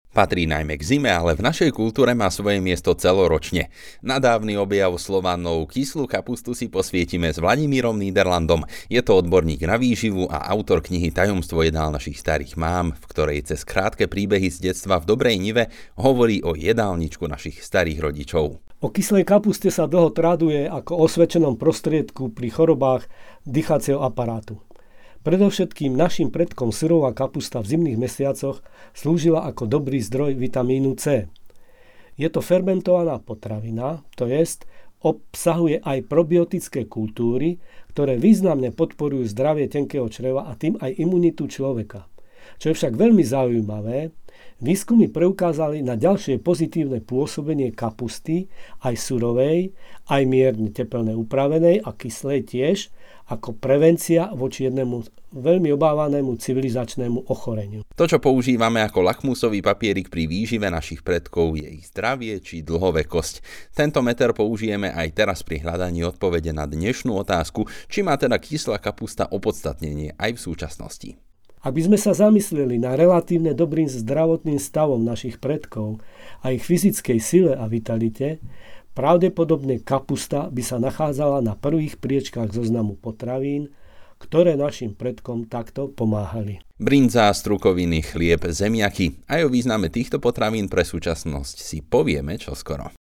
Rozhlasové šoty o výžive našich predkov